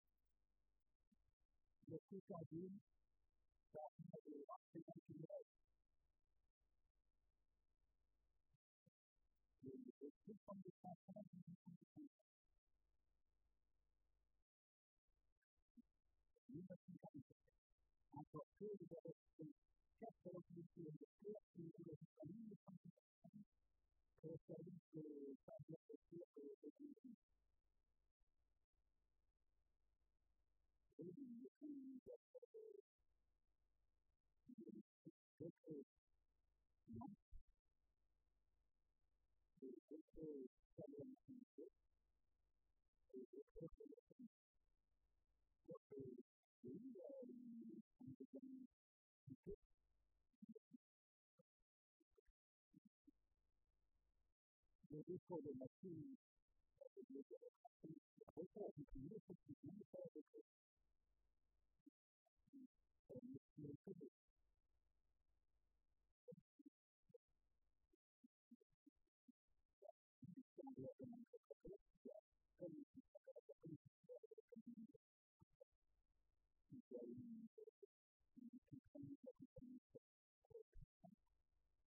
Conférence sur le Petit Anjou
Catégorie Témoignage